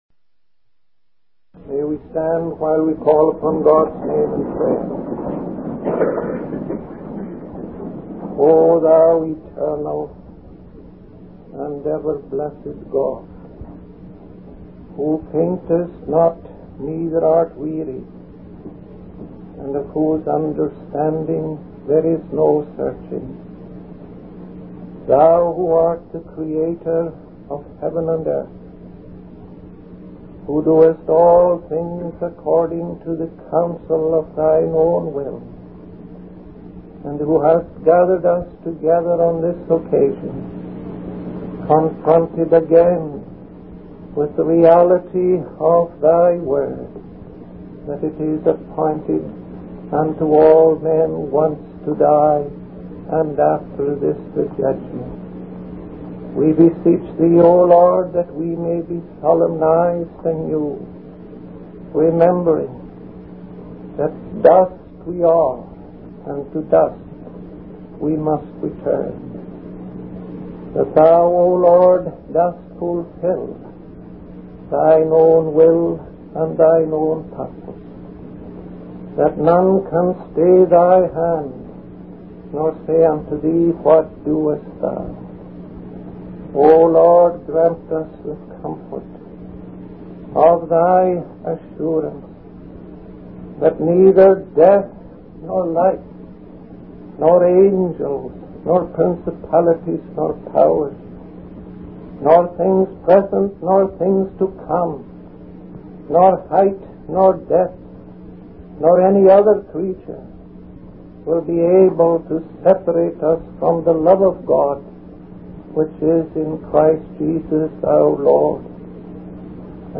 In this sermon, the preacher emphasizes the importance of focusing our thoughts and hearts on the glorious event of Christ's manifestation in his matchless glory.